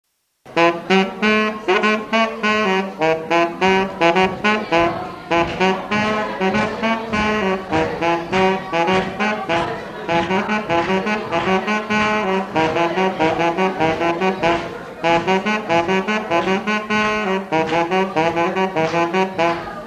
Chants brefs - A danser
Pièce musicale inédite